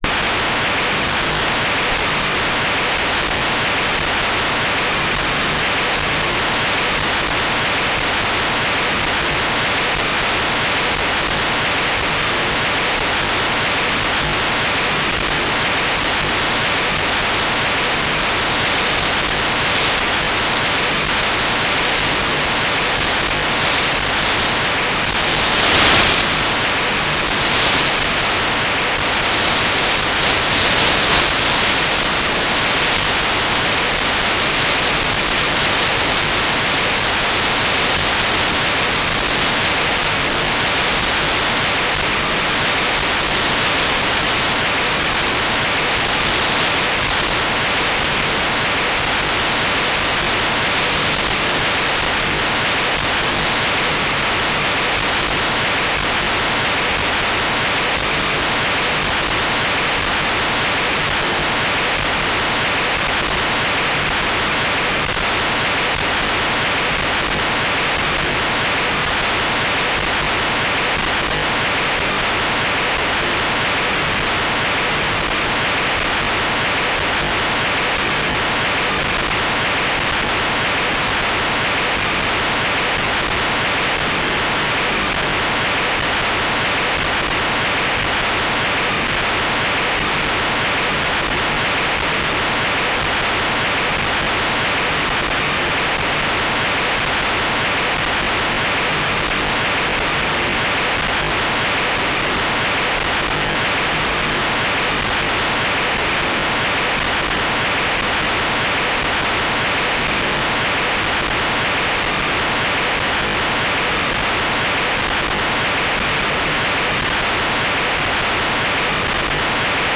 "transmitter_description": "Telemetry",